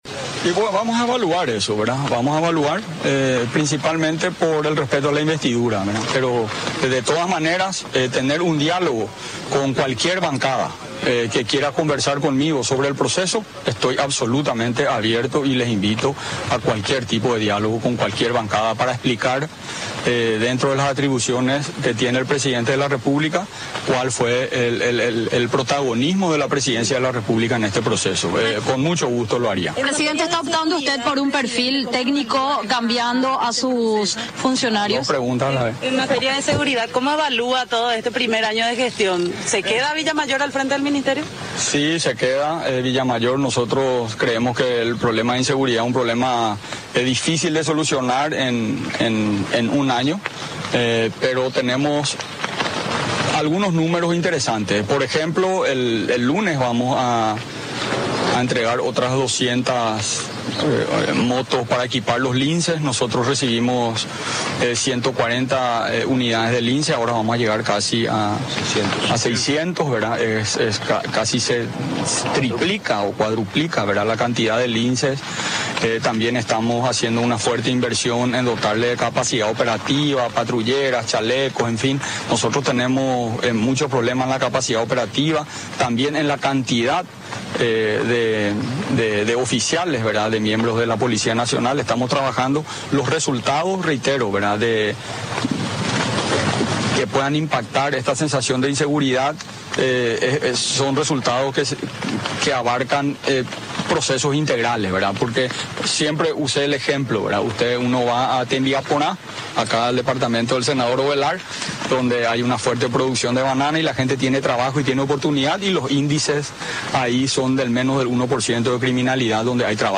“Hablé con Horacio Cartes, es un gesto que tengo que reconocer”, expresó Abdo ante los medios tras el acto oficial festivo por la fundación de Asunción, celebrado en el jardín del Palacio de López.